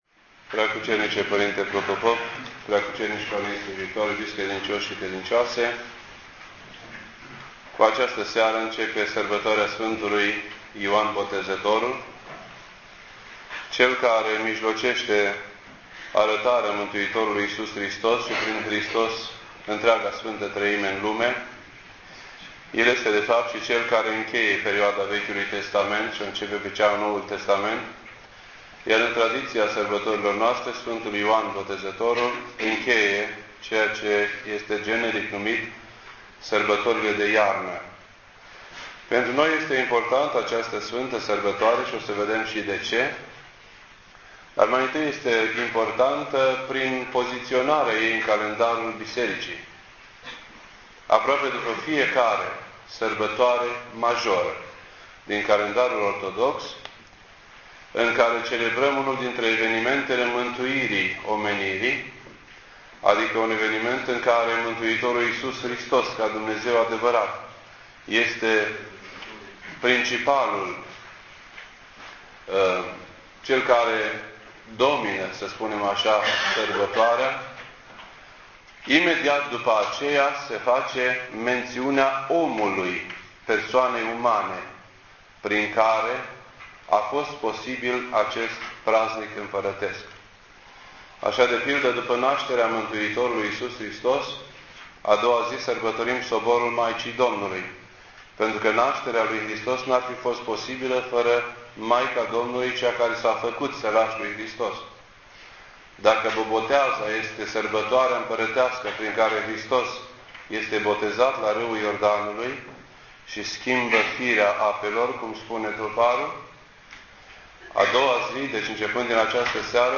This entry was posted on Wednesday, January 7th, 2009 at 7:05 PM and is filed under Predici ortodoxe in format audio.